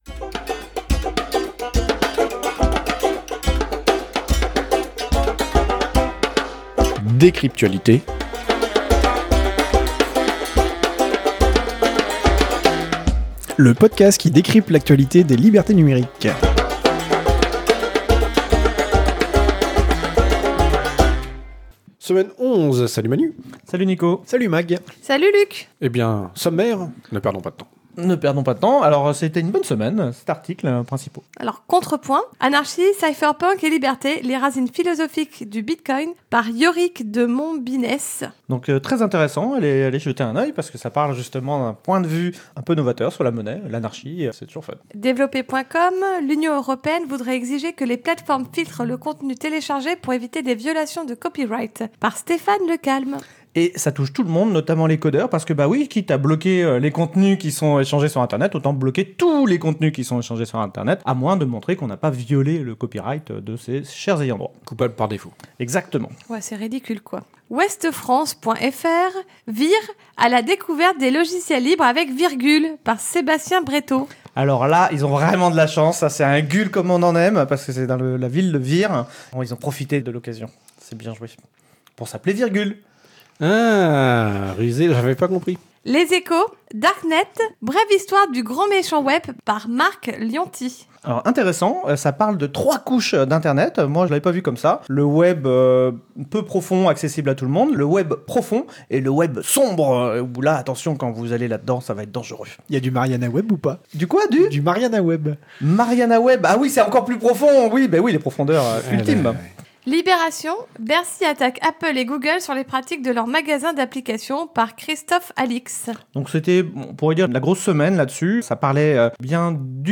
Lieu : Studio d'enregistrement April